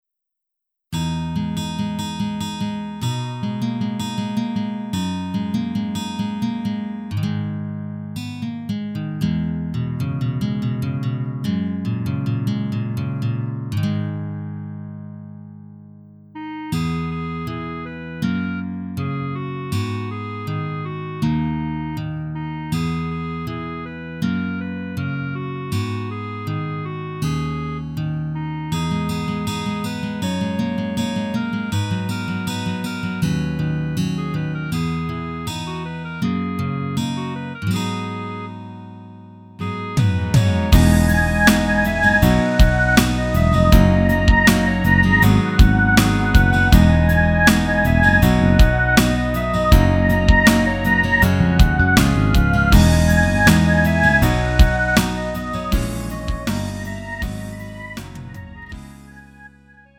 음정 -1키 3:39
장르 구분 Lite MR